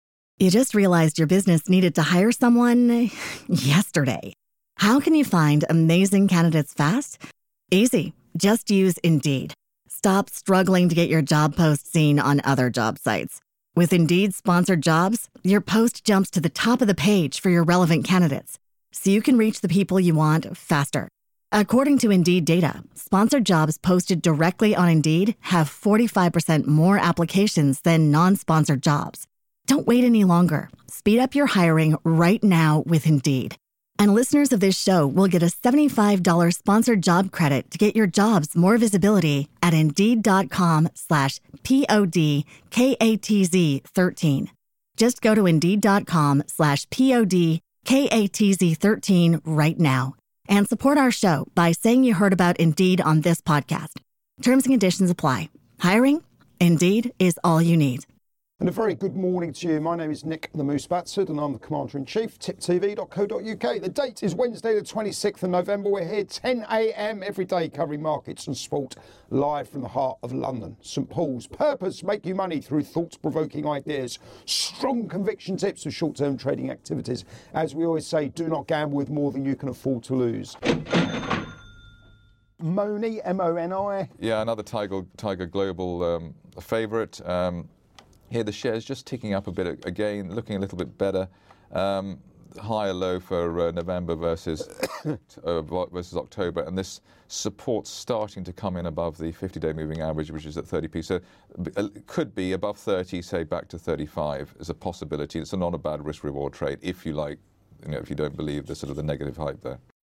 Live Market Round-Up